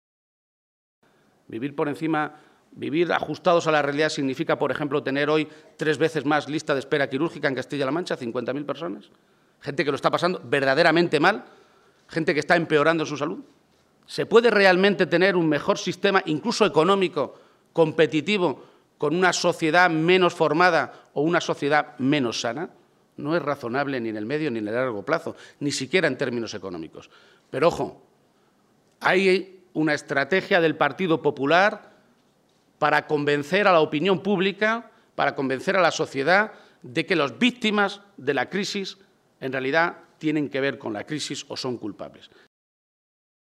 Page se pronunciaba de esta manera durante los Diálogos Ganarse el Futuro organizados a nivel nacional por el PSOE y que este domingo tenían a la ciudad de Albacete como sede para discutir sobre el modelo de Sanidad y servicios sociales.